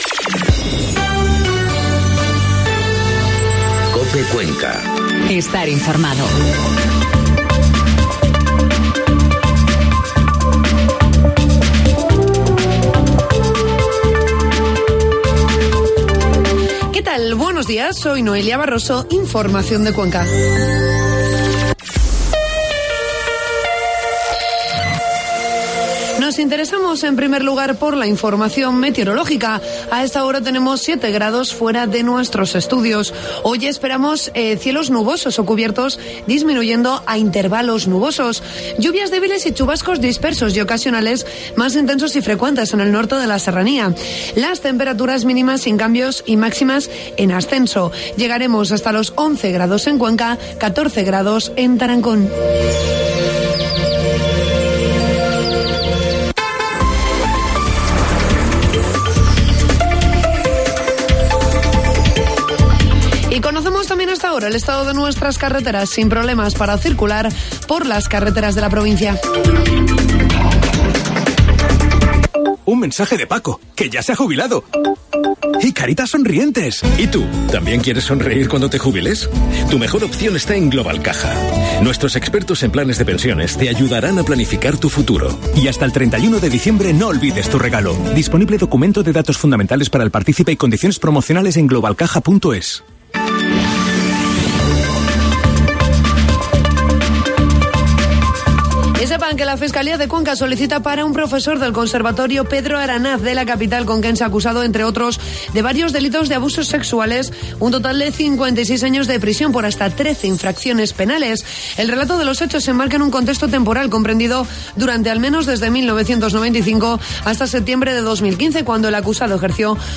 Informativo matinal COPE Cuenca 7 de noviembre